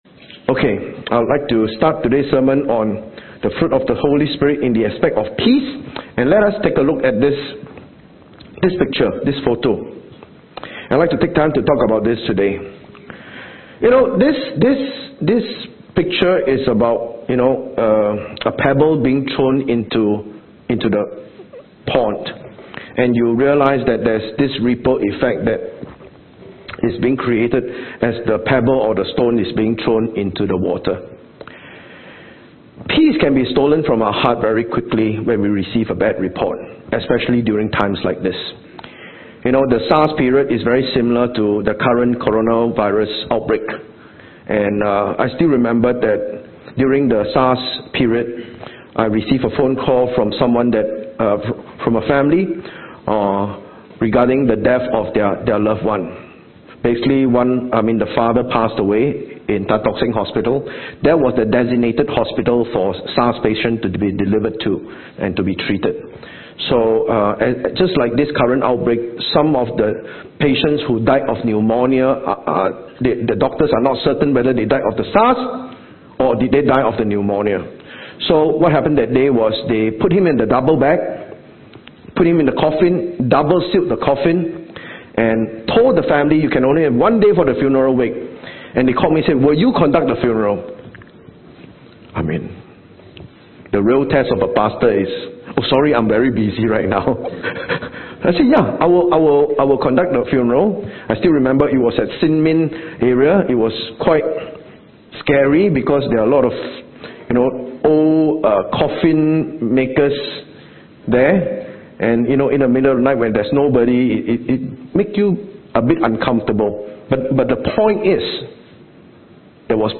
Sermon
10am service